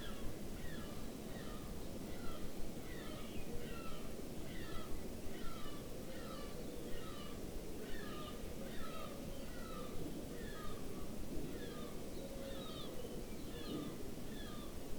▶ Sound of MEMS with correct bit depth, shielded and short cables, stereo
These recordings are from a morning in Bristol, and yes, we have a lot of seagulls here.
5_mems_stereo_32-bit-as-intended_short-cable.wav